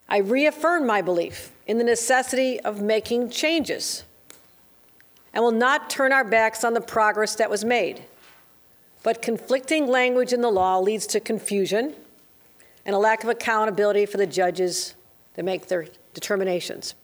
When she presented her fiscal year 2024 budget proposal, Governor Kathy Hochul said she admits some changes to the laws are necessary.